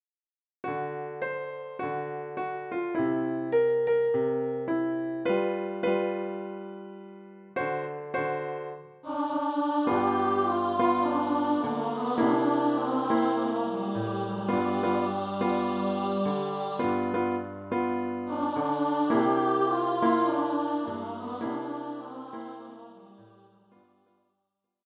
für Gesang, mittlere Stimme